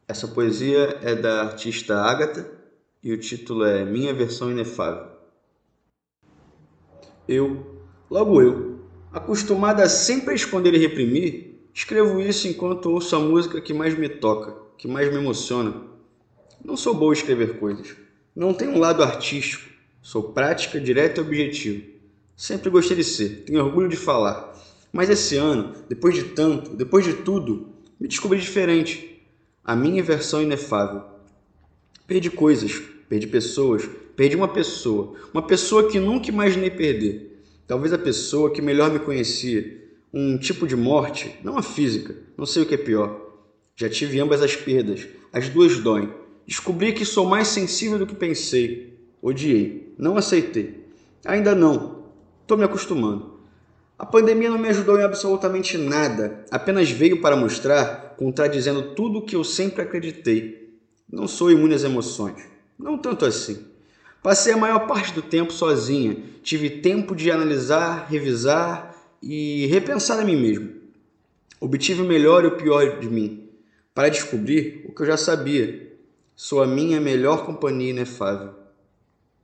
Poesia com voz humana